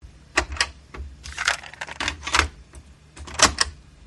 Descarga de Sonidos mp3 Gratis: colocar casette.
pushing-in-old-cassette.mp3